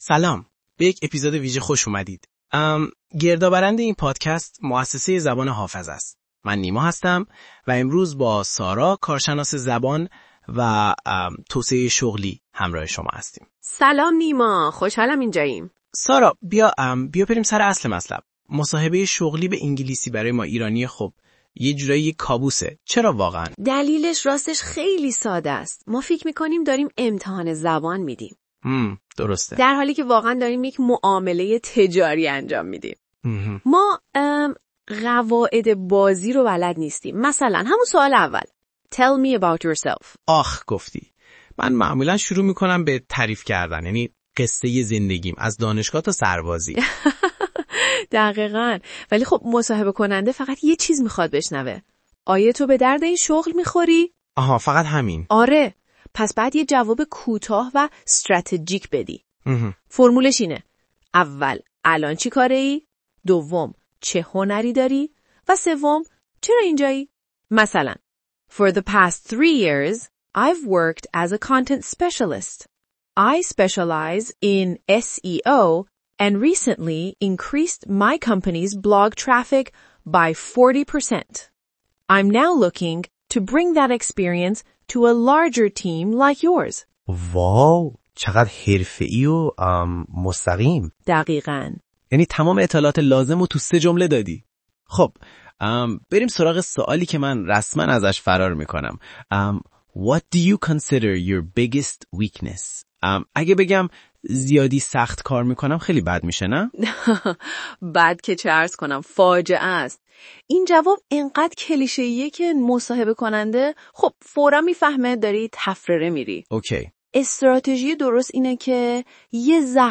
job-interview.mp3